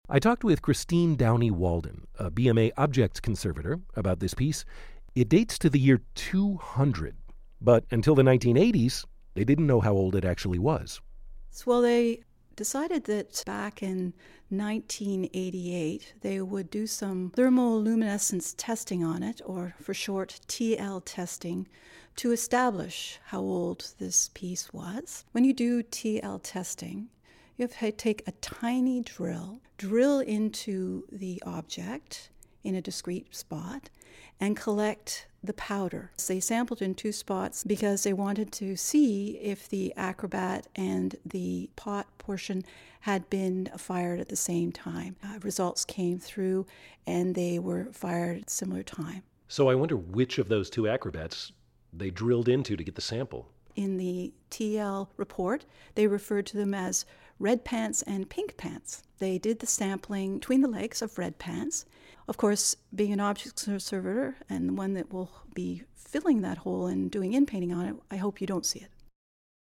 Tripod-Vessel-with-Acrobats_-A-conversation-with-a-conservator.mp3